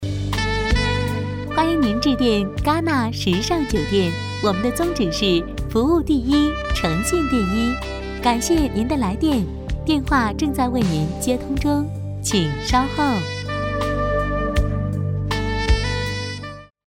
男国135_专题_学校_定西凌空飞舞艺术学校_激情-新声库配音网
标签： 激情